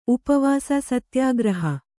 ♪ upavāsa satyāgraha